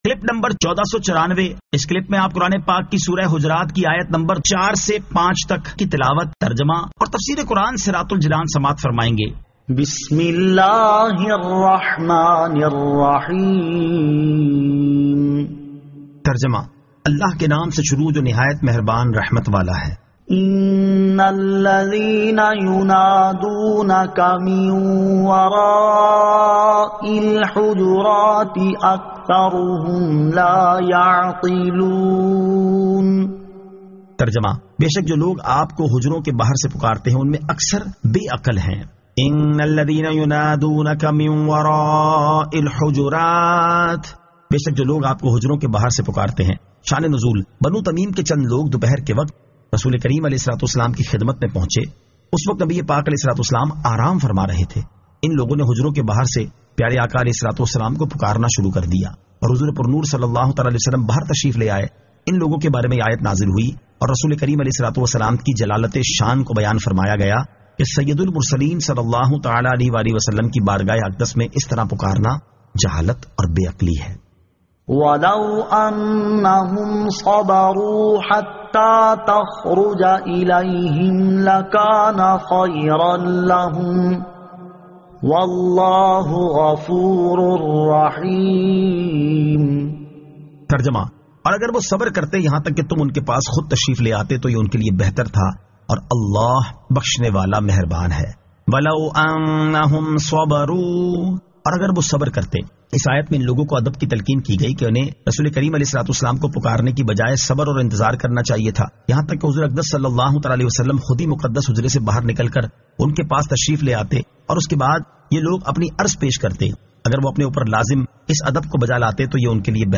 Surah Al-Hujurat 04 To 05 Tilawat , Tarjama , Tafseer